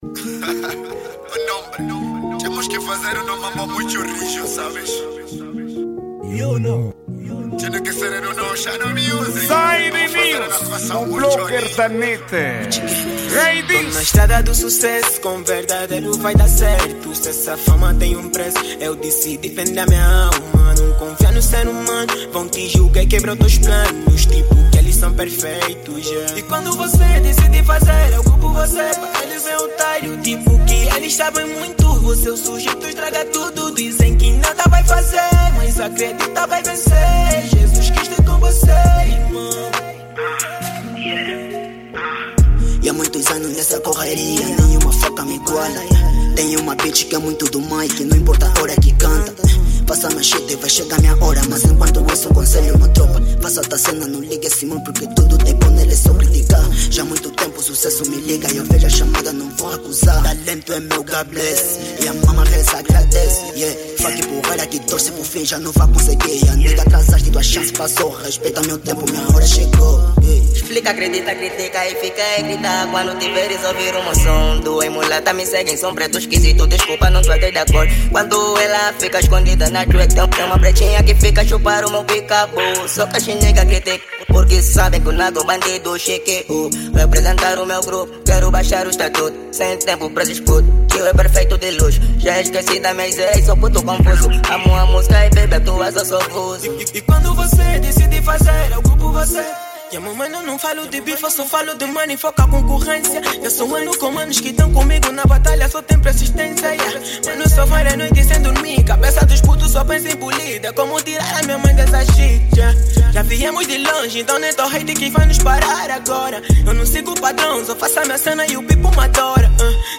Gênero:Rap